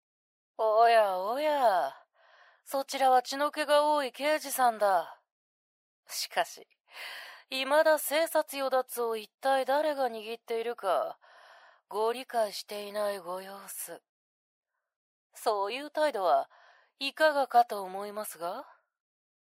サンプルボイス